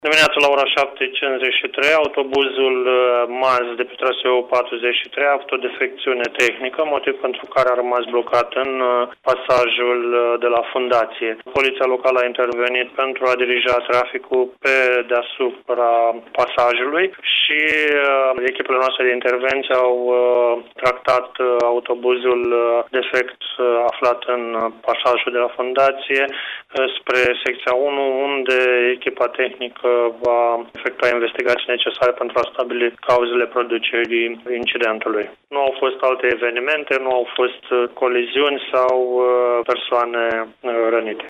Contactat telefonic